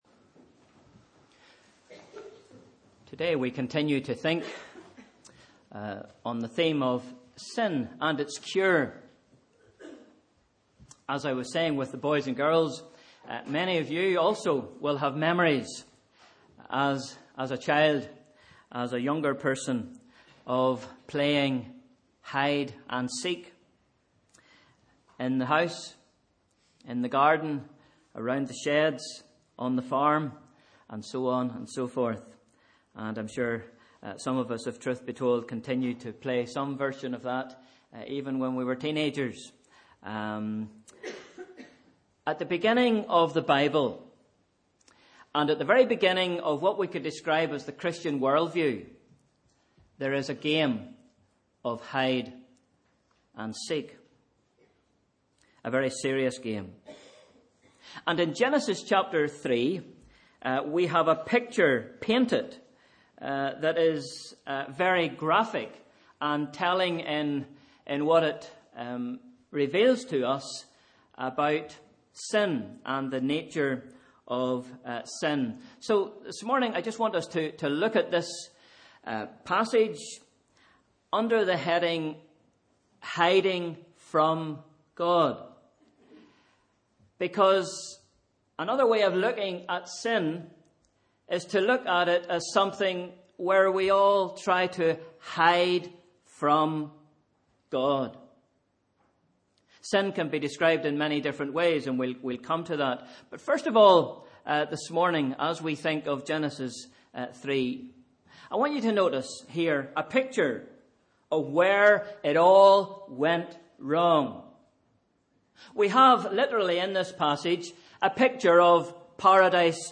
Sunday 24th January 2016 – Morning Service